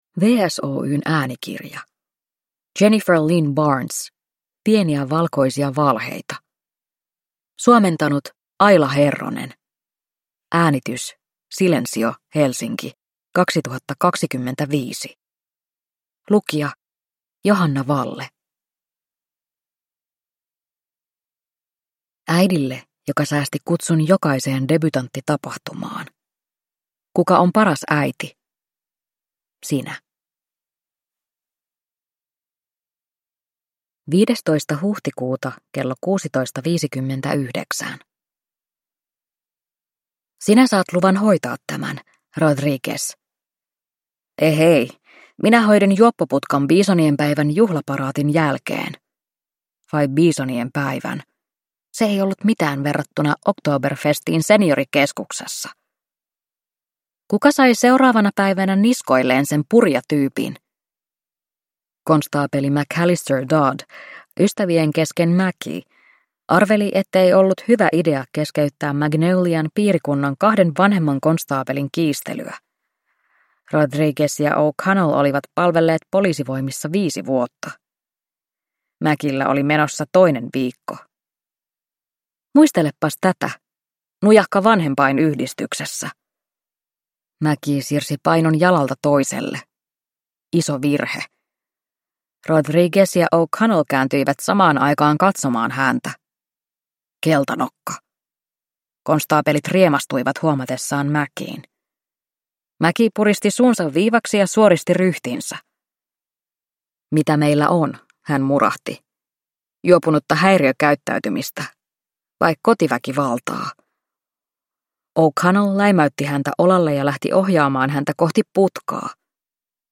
Pieniä valkoisia valheita – Ljudbok